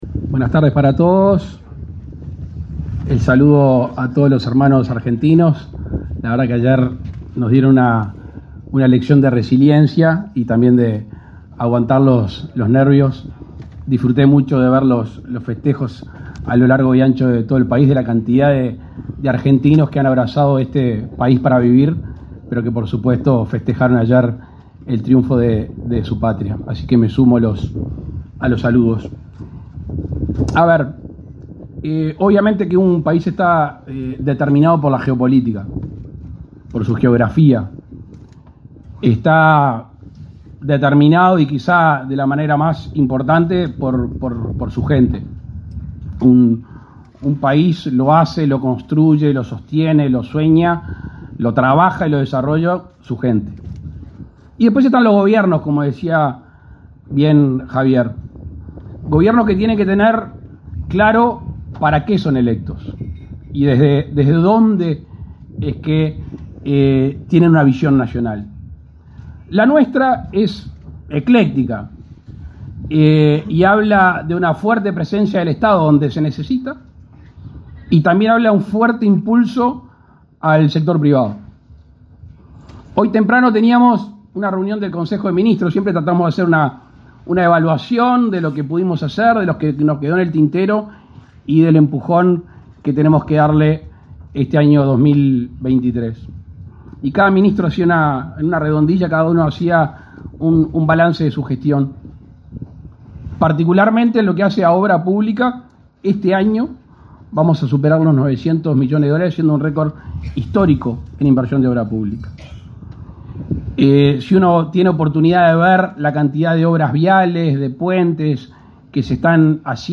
Palabras del presidente de la República, Luis Lacalle Pou
Con la presencia del presidente de la República, Luis Lacalle Pou, se realizó, este 19 de diciembre, la inauguración del aeropuerto internacional de